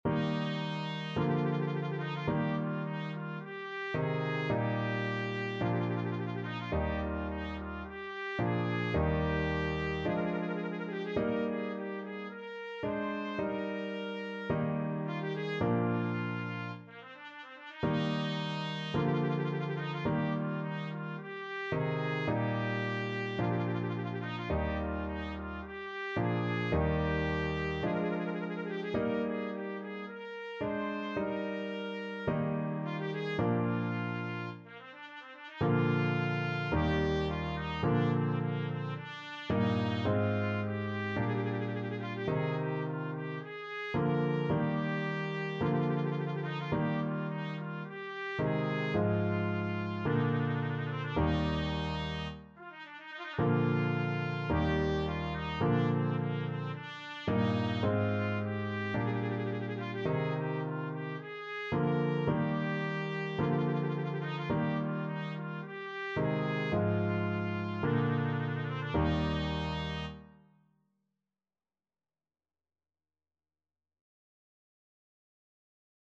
Trumpet
Eb major (Sounding Pitch) F major (Trumpet in Bb) (View more Eb major Music for Trumpet )
= 54 Slow
Classical (View more Classical Trumpet Music)